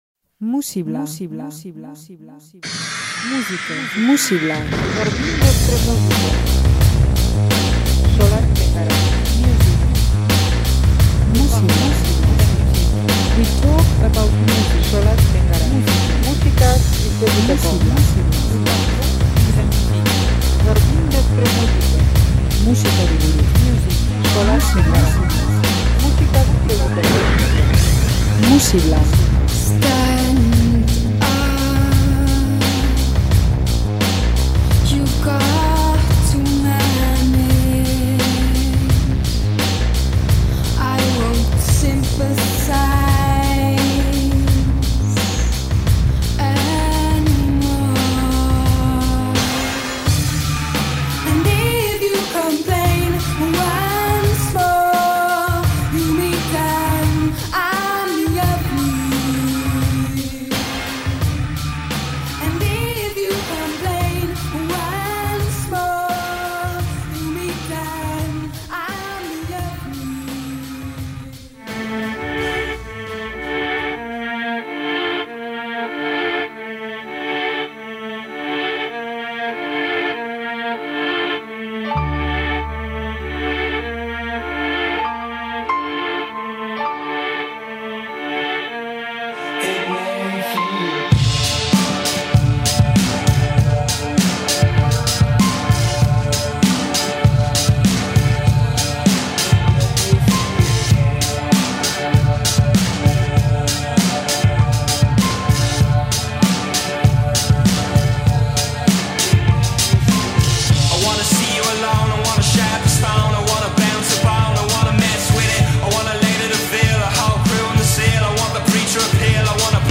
folk eta rockaren mugetan jolasean